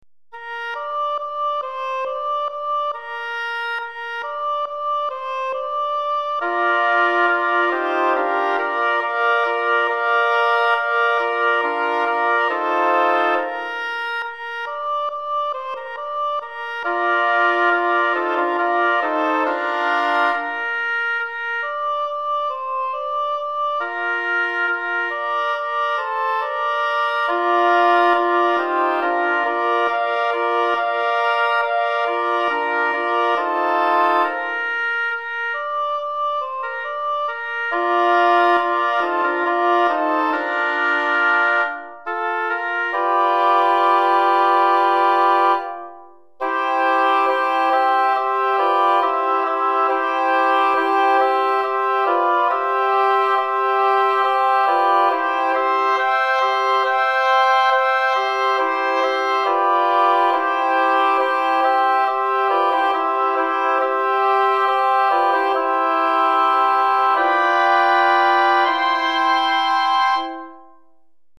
4 Hautbois